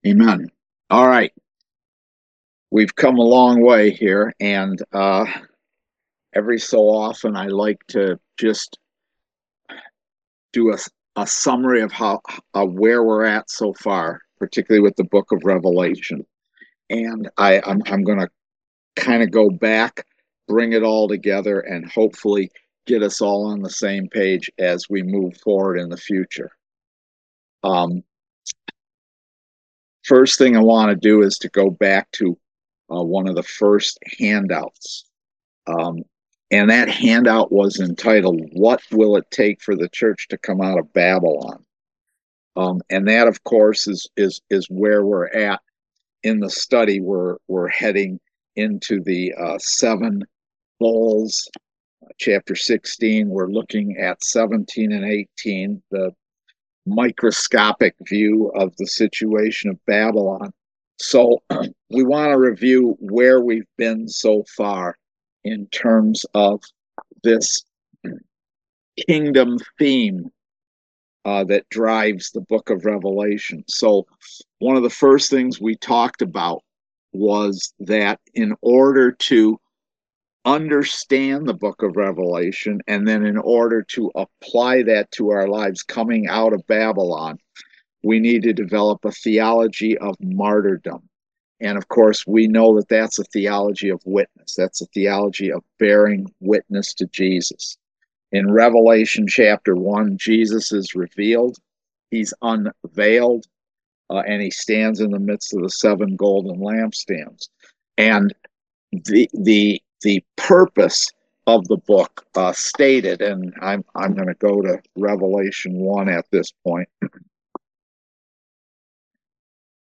Service Type: Kingdom Education Class